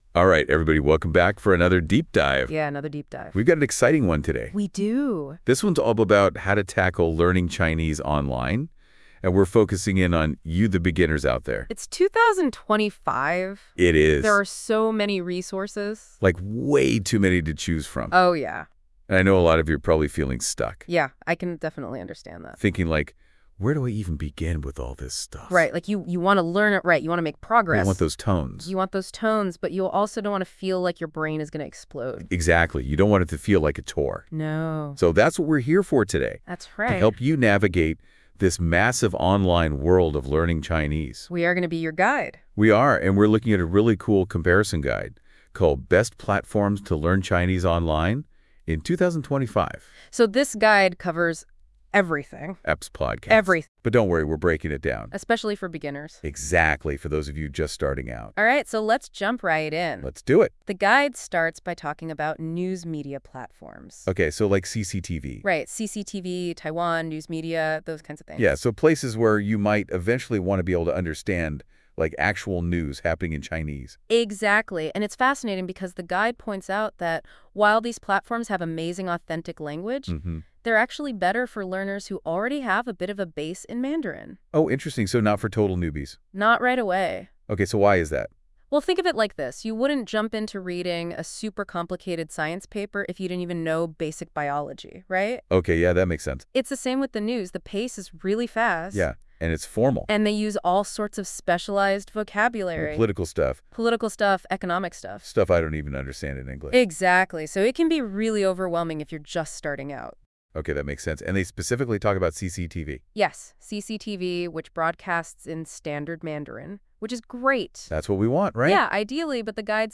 Audio guide comparing online Chinese learning platforms